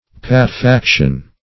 Search Result for " patefaction" : The Collaborative International Dictionary of English v.0.48: Patefaction \Pat`e*fac"tion\, n. [L. patefactio, fr. patefacere to open; patere to lie open + facere to make.]